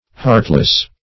Heartless \Heart"less\, a.